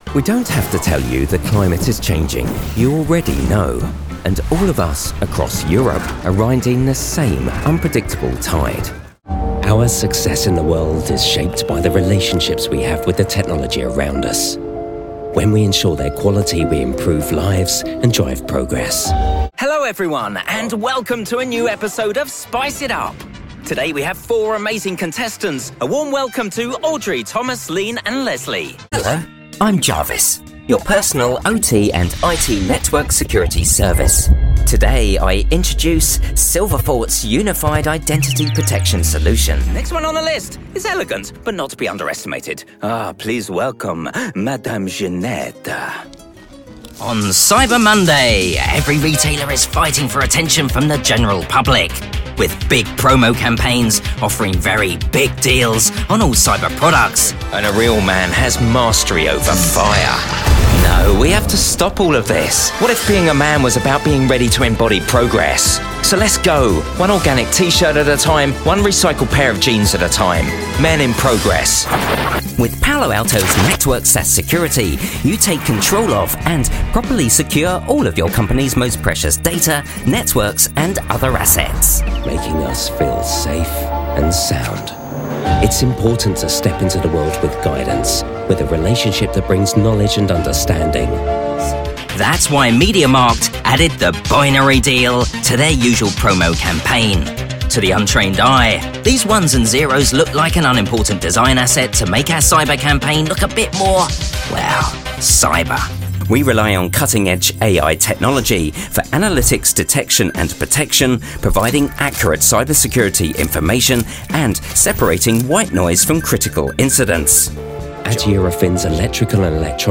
English (British)
Cool, Friendly, Warm, Commercial, Corporate
Corporate